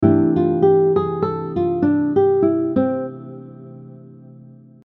The first 3 application examples are practical melodic lines using the Major 6 diminished scale over a C Major chord.
Major-6-diminished-scale-example-2.mp3